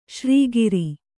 ♪ śrī giri